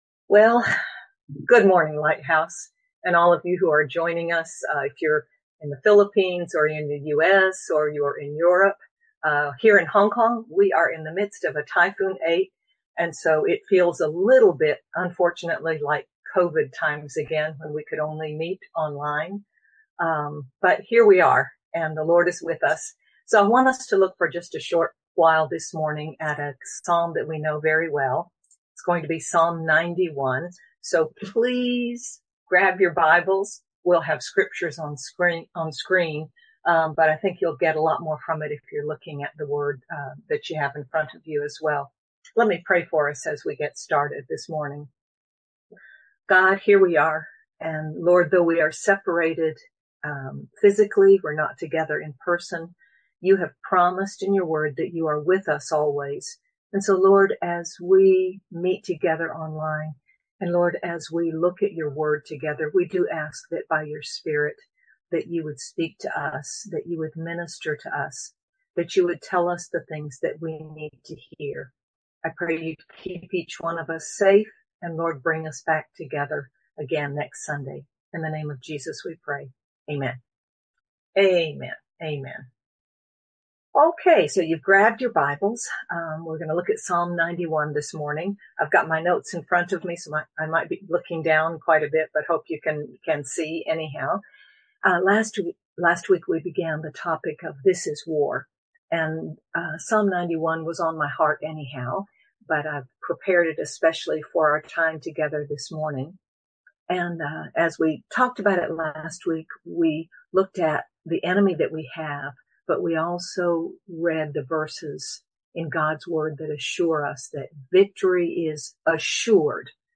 Psalm 91 tells us about our God who defends us Sermon by